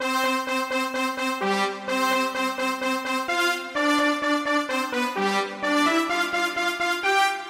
Tag: 128 bpm House Loops Brass Loops 2.52 MB wav Key : A